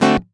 OVATION D7 K.wav